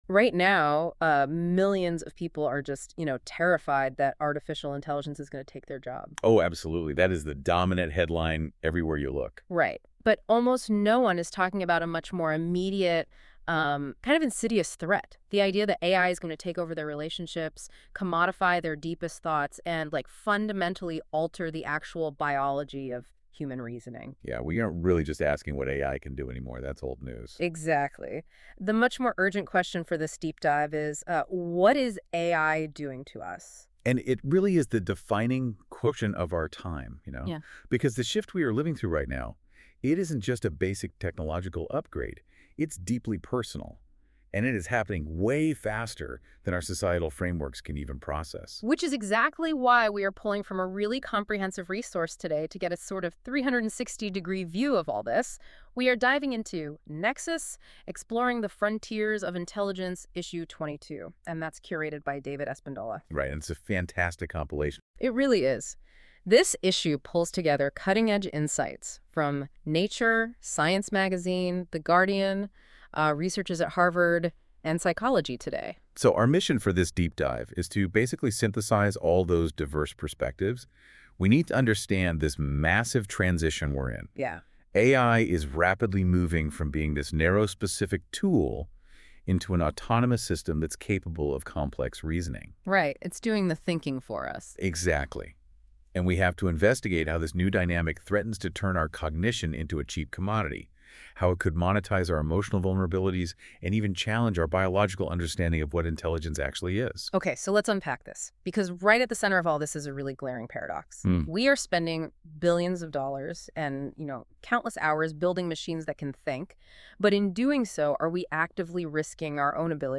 Nexus Deep Dive is an AI-generated conversation in podcast style where the hosts talk about the content of each issue of Nexus: Exploring the Frontiers of Intelligence .